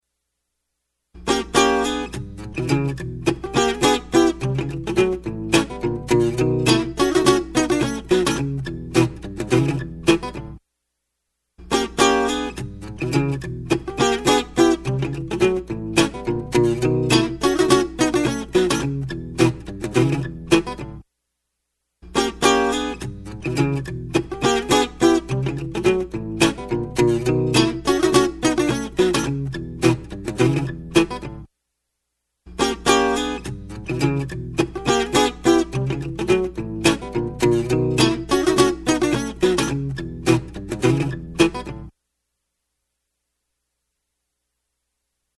In each of the following listening tests you will hear four playbacks of a musical performance clip. Three of the clips were recorded with vintage Neve 1073 modules and one of the clips was recorded with a Vintech X73 preamp. In each case one mic was passively split to all four preamps so that each preamp could amplify the same exact performance utilizing the same exact microphone. The only variable is the preamps.
Acoustic Guitar (sorry, cheap miniatrue acoustic)
acoustic guitar test.mp3